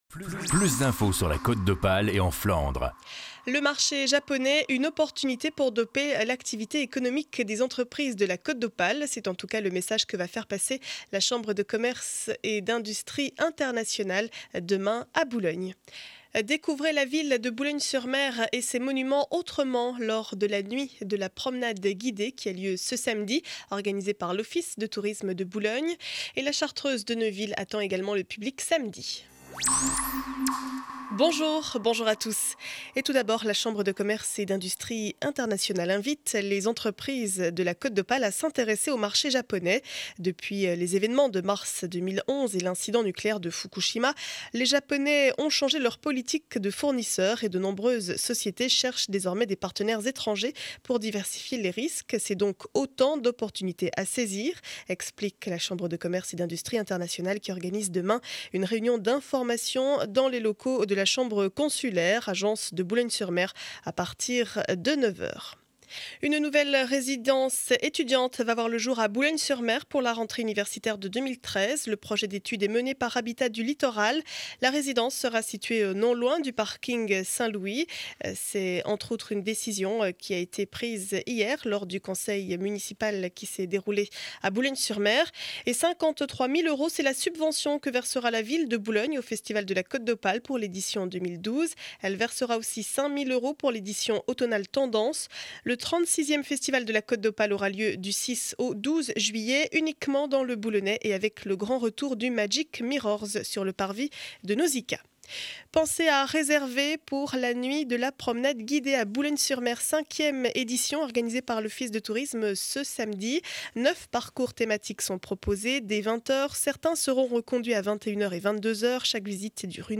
Journal du mardi 03 avril 2012 7 heures 30 édition du Boulonnais.